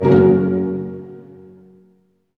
Index of /90_sSampleCDs/Roland LCDP08 Symphony Orchestra/HIT_Dynamic Orch/HIT_Tutti Hits